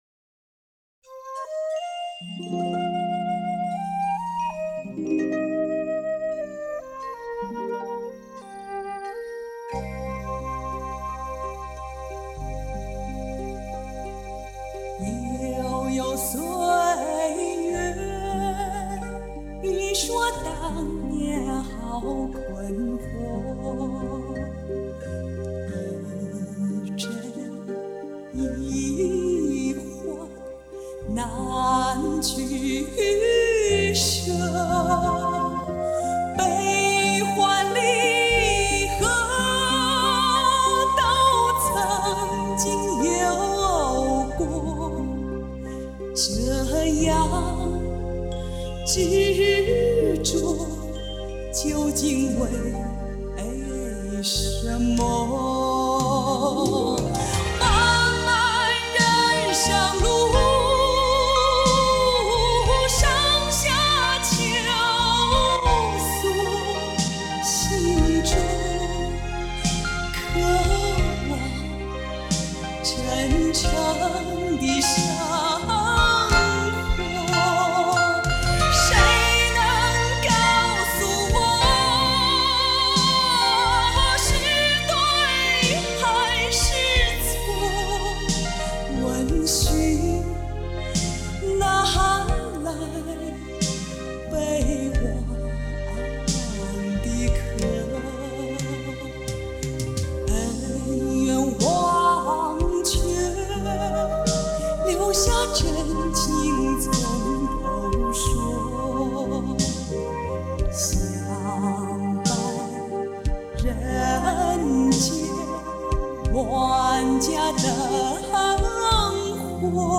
Ps：在线试听为压缩音质节选，体验无损音质请下载完整版 https